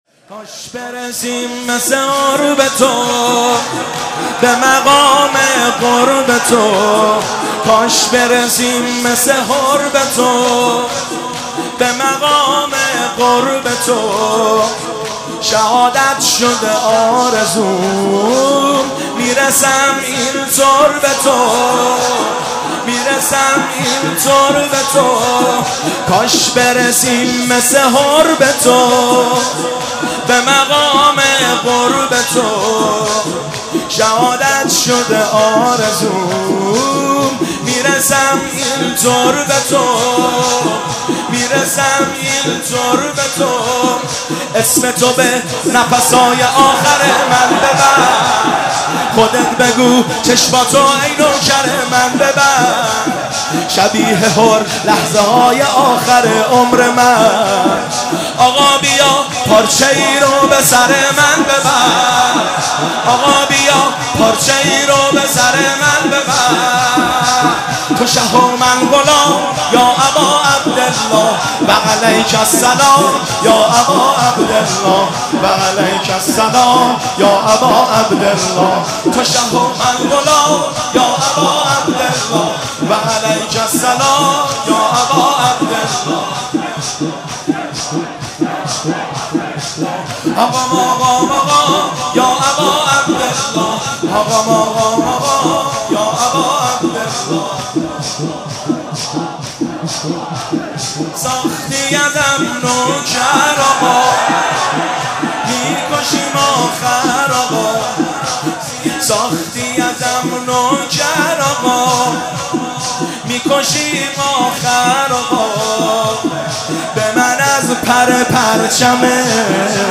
شب سوم محرم 1392
هیئت خادم الرضا(ع) قم